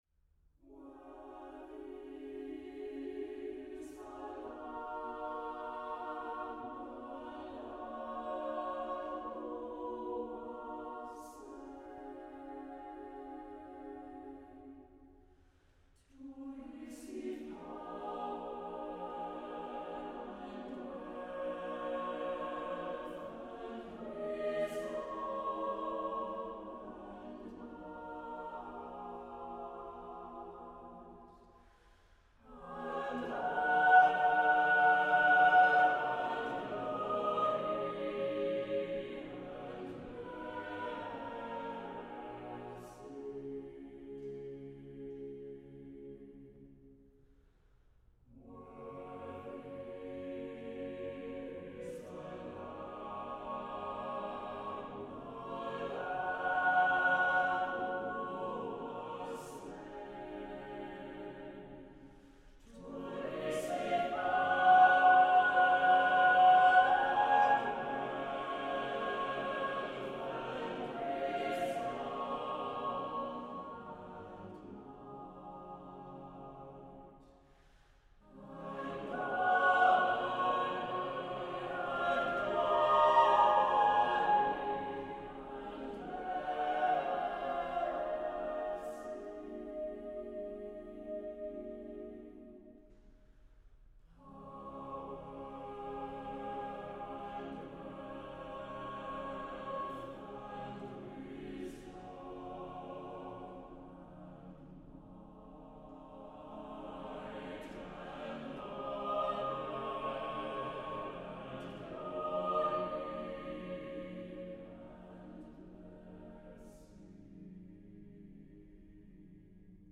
Accompaniment:      None
Music Category:      Choral